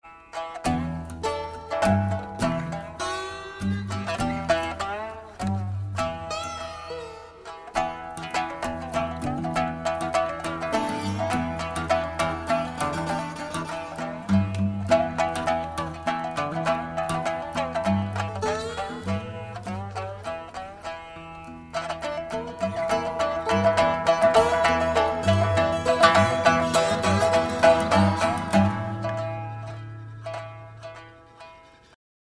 • Качество: 128, Stereo
гитара
OST